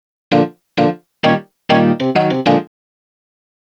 Swinging 60s 1 Organ-A#.wav